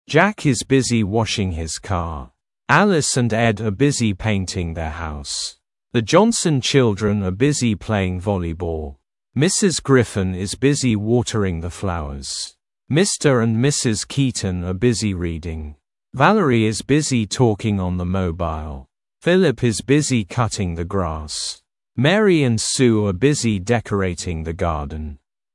Произношение:
1) Jack is busy washing his car. – [Джэк из бизи уошинг хиз каː] – Джек занят мытьем своей машины.